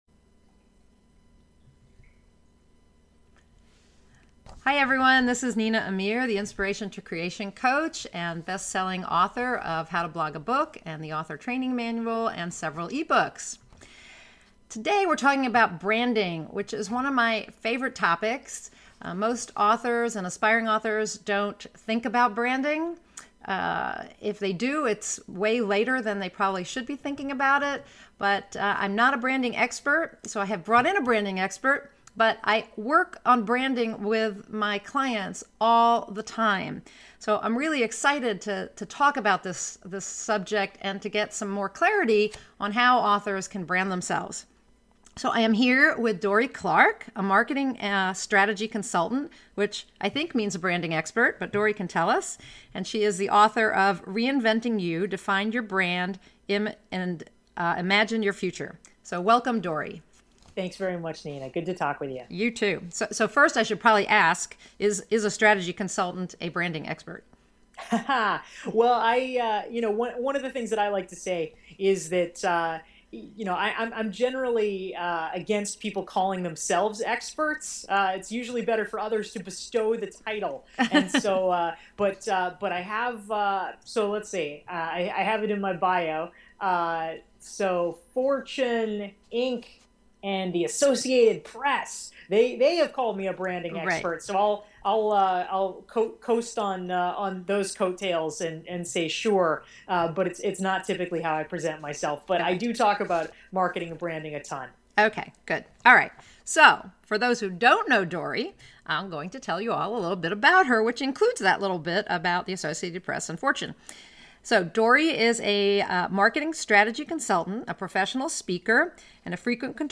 Interview with Dorie Clark